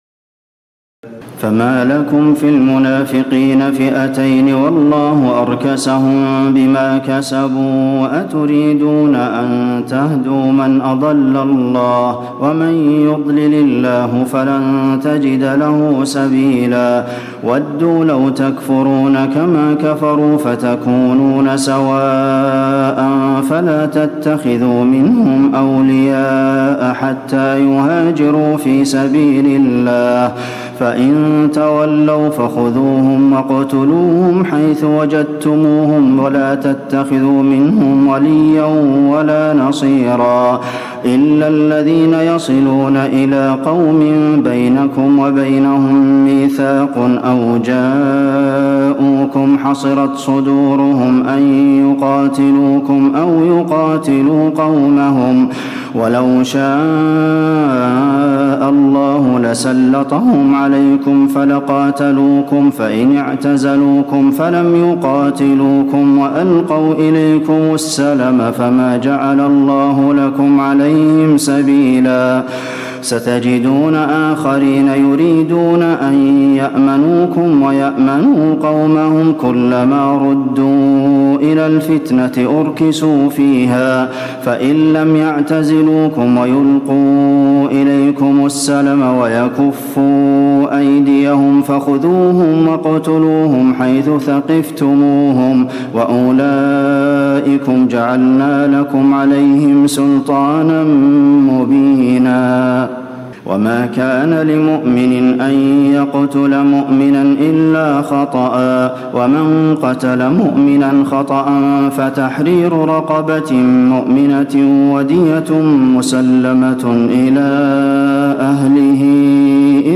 تراويح الليلة الخامسة رمضان 1436هـ من سورة النساء (88-147) Taraweeh 5 st night Ramadan 1436H from Surah An-Nisaa > تراويح الحرم النبوي عام 1436 🕌 > التراويح - تلاوات الحرمين